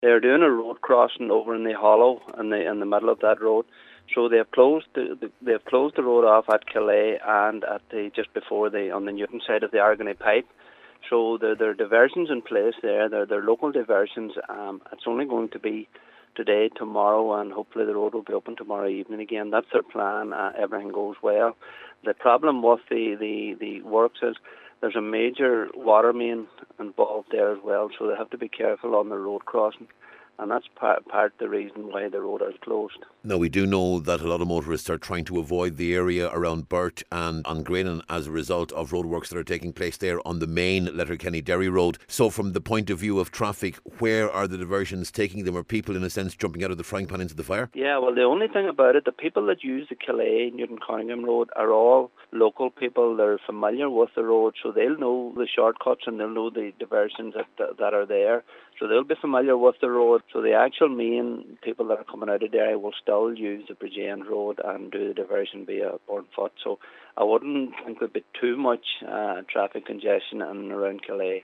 The road closure is due to last until Friday, but local councillor Paul Canning is hopeful it can reopen tomorrow evening……………